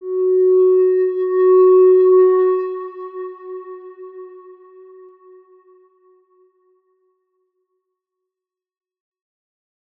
X_Windwistle-F#3-pp.wav